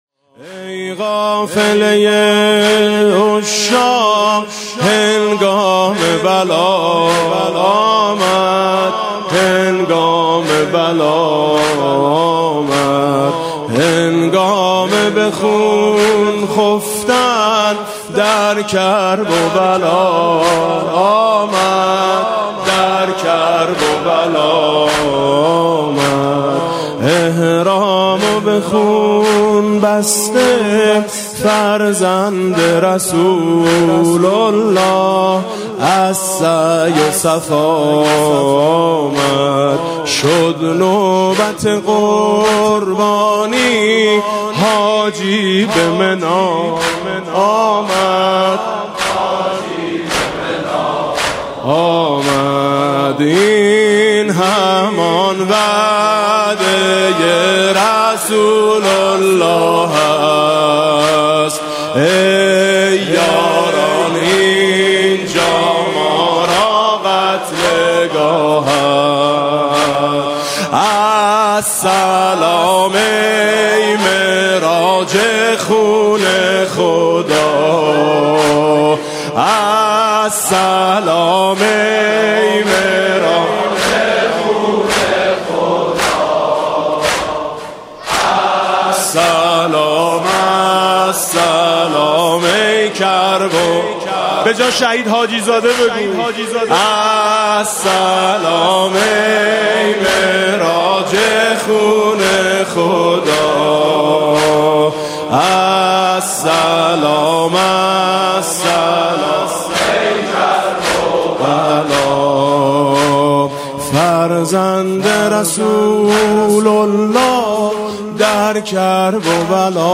سبکی متفاوت از مداحی میثم مطیعی در دهه اول محرم + صوت و متن
میثم مطیعی همزمان با دهه اول ماه محرم در هیئت آئین حسینی به سبک سنتی به مرثیه خوانی پرداخت.